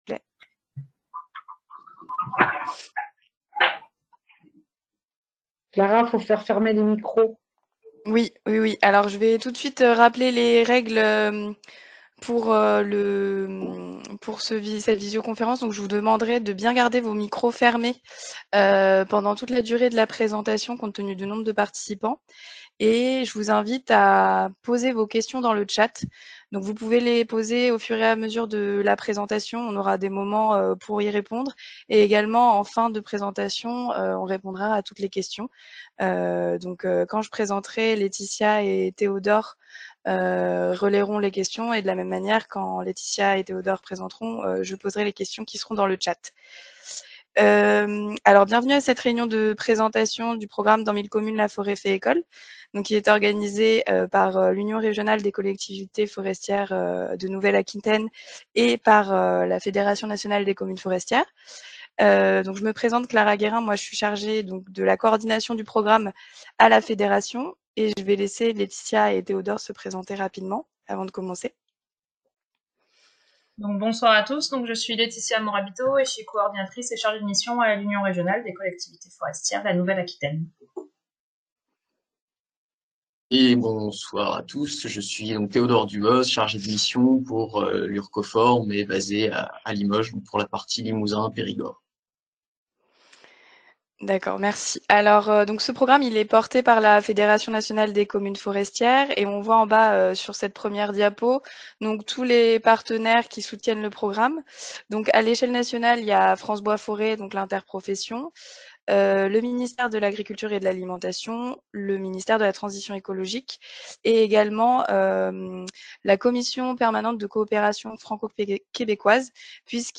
– l’enregistrement de la réunion